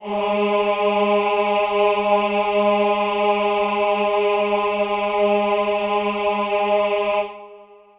Choir Piano (Wav)
G#3.wav